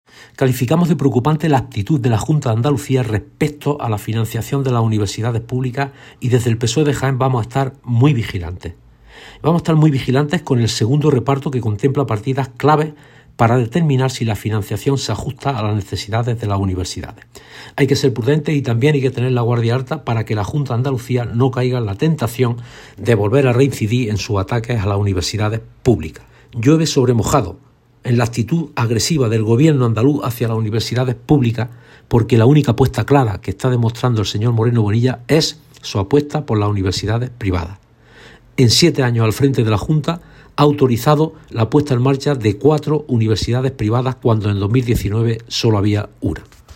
Cortes de sonido # Jacinto Viedma